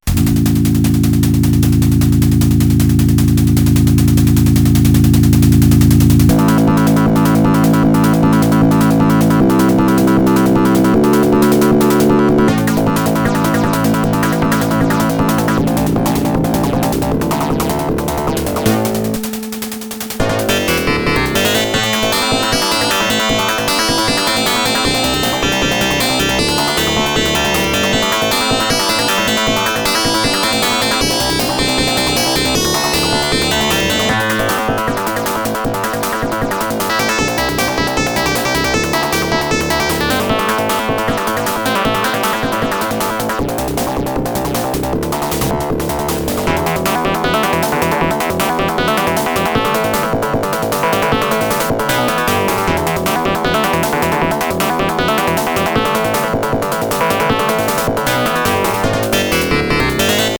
Aztech Washington 16 (AZT2346A + YMF262-M OPL3) (